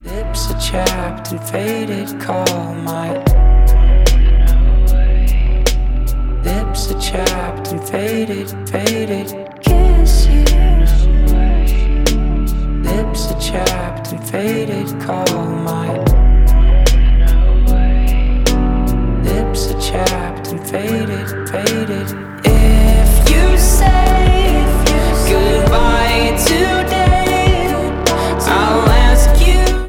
• Alternative